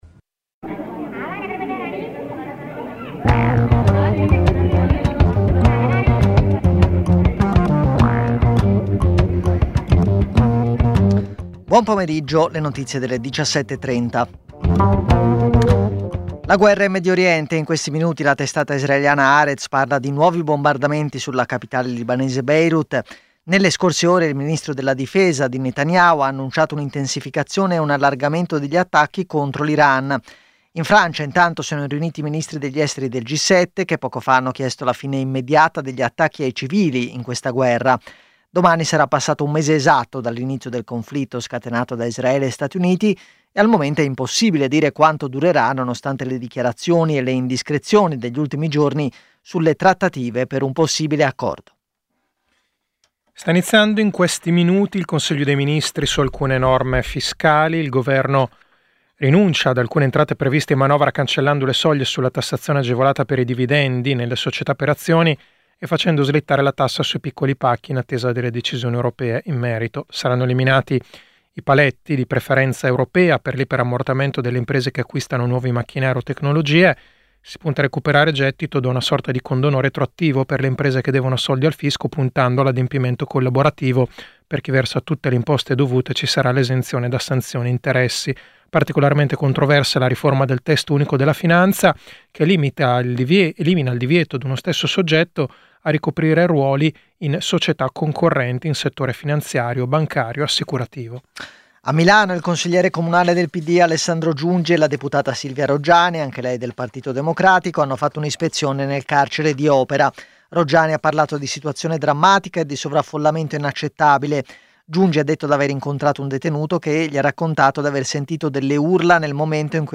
Edizione breve del notiziario di Radio Popolare. Le notizie. I protagonisti. Le opinioni. Le analisi.